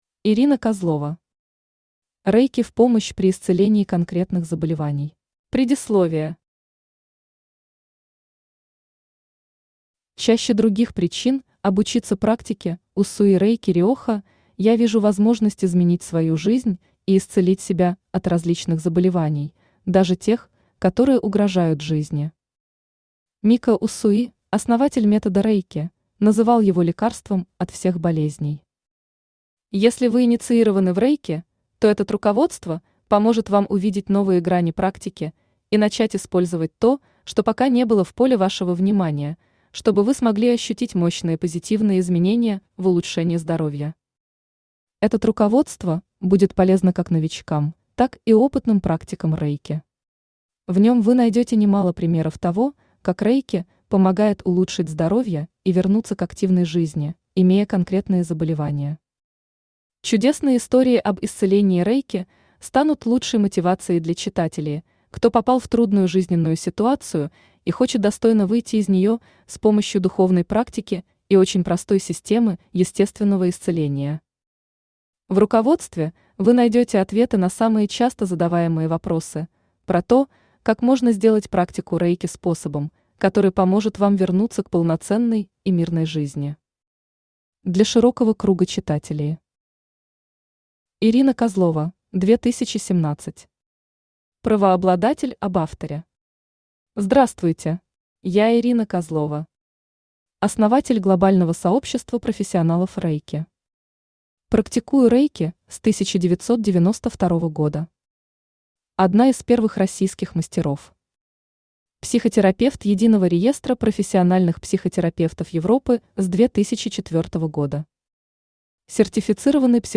Аудиокнига Рэйки в помощь при исцелении конкретных заболеваний | Библиотека аудиокниг
Aудиокнига Рэйки в помощь при исцелении конкретных заболеваний Автор Ирина Александровна Козлова Читает аудиокнигу Авточтец ЛитРес.